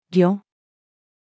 旅-500-man-battalion-(Zhou-dynasty-Chinese-army)-female.mp3